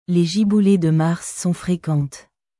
Les giboulées de mars sont fréquentesレ ジブゥレ ドゥ マァルス ソン フレクォントゥ